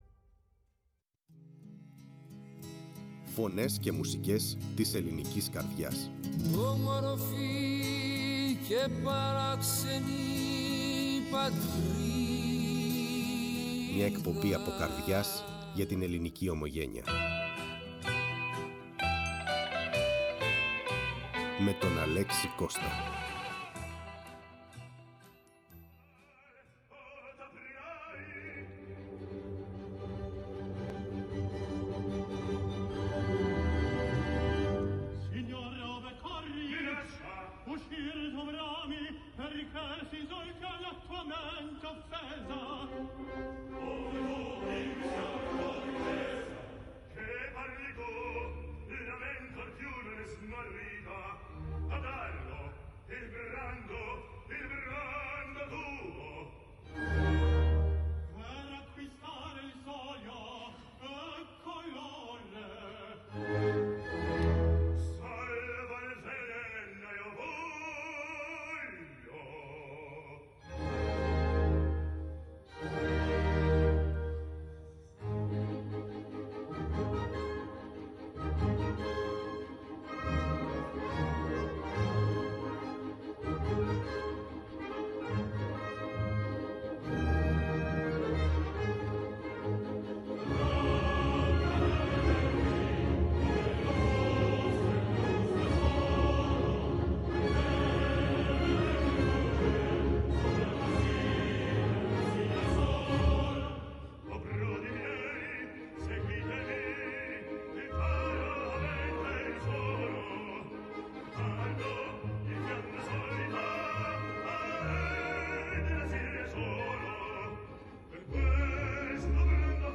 φιλοξένησε ζωντανά στο στούντιο της Φωνής της Ελλάδας τρεις κορυφαίους καλλιτέχνες